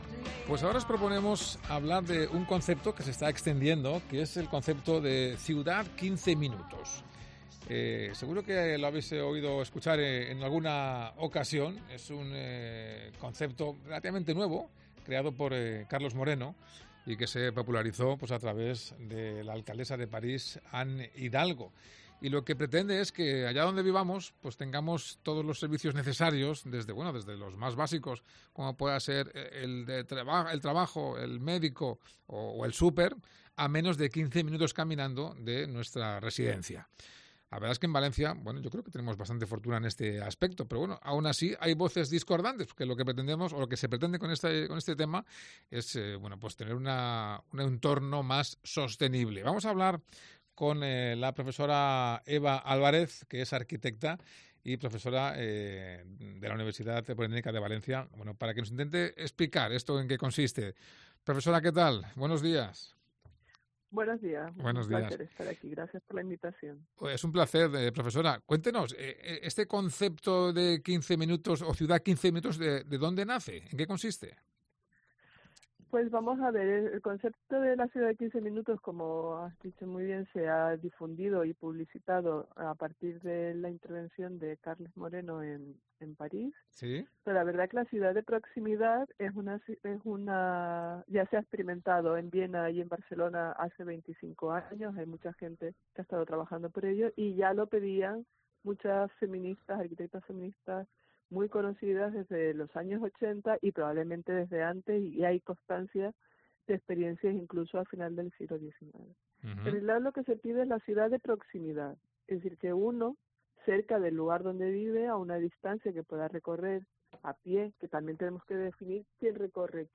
Mediodía de COPE Más Valencia